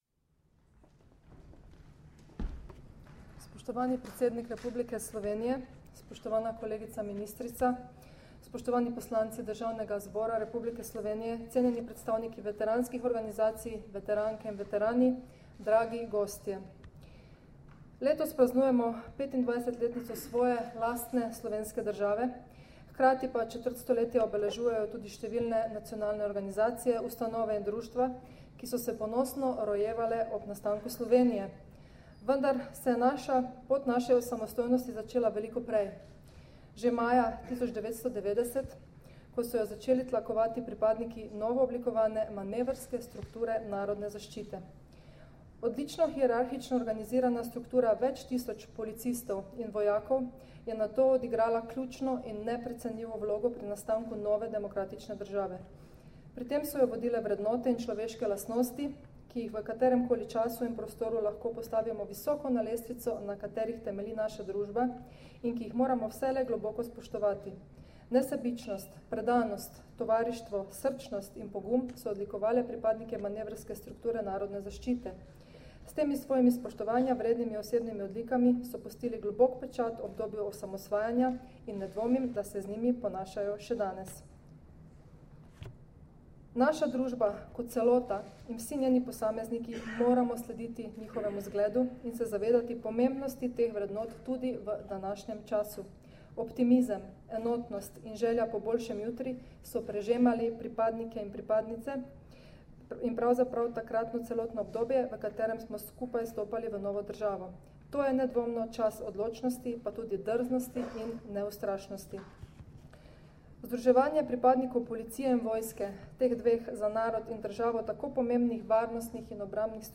Zvočni posnetek izjave ministrice za notranje zadeve mag. Vesne Györkös Žnidar (mp3)